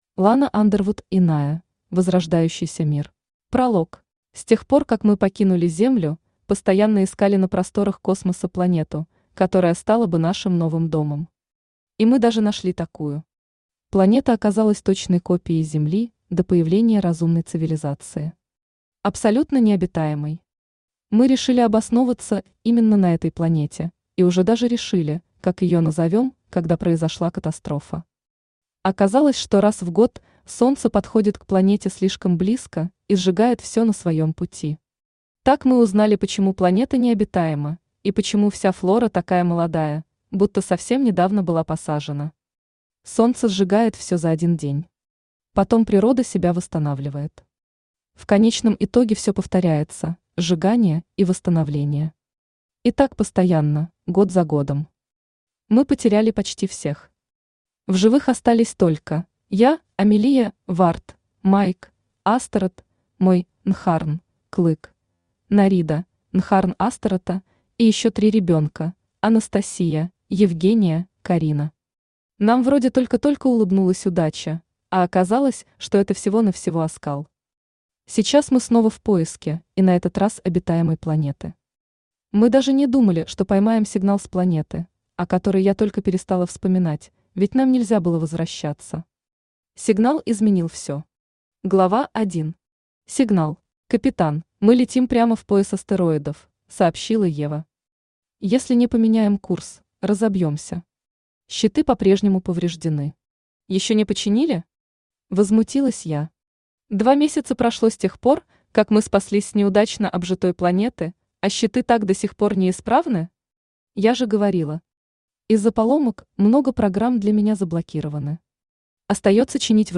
Аудиокнига Иная. Возрождающийся мир | Библиотека аудиокниг
Возрождающийся мир Автор Лана Мейс Андервуд Читает аудиокнигу Авточтец ЛитРес.